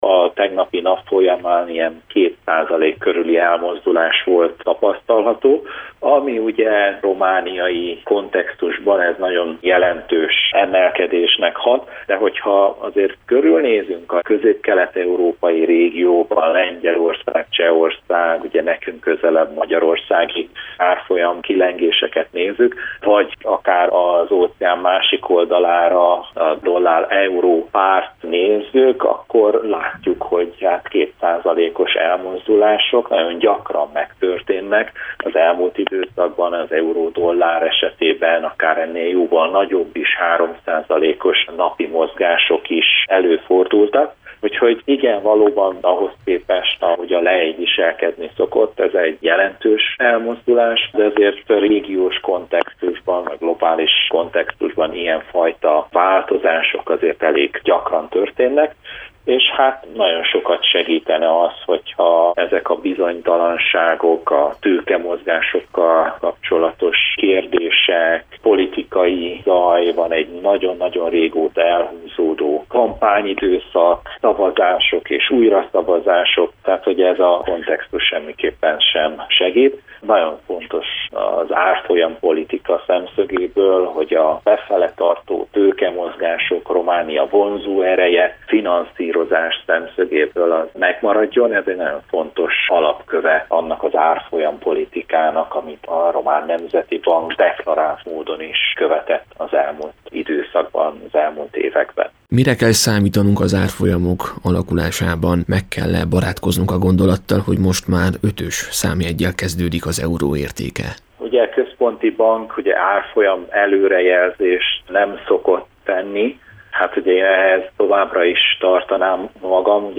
A BNR igazgatótanácsának a tagja az intézmény helyzetkezelését is ismertette az interjú során. https